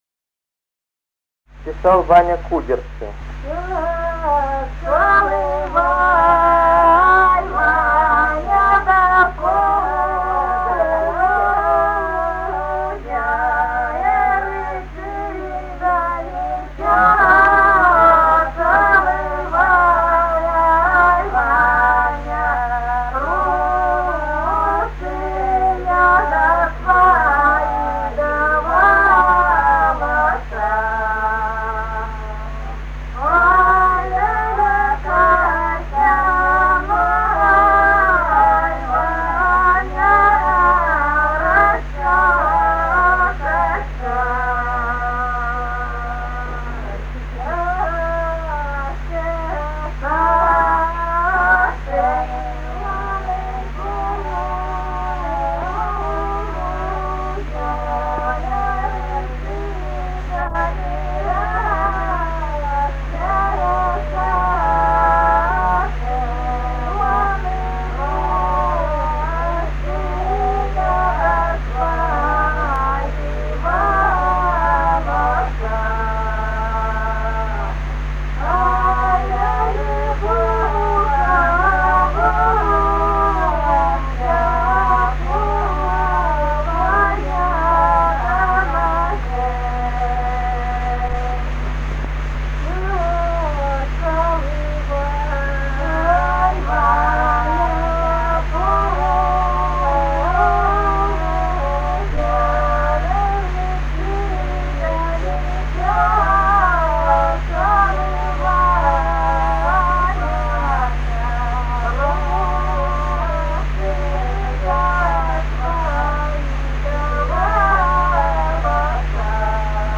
Русские народные песни Красноярского края.
«Чёсал Ваня да кудёрцы» (протяжная). с. Яркино Богучанского района.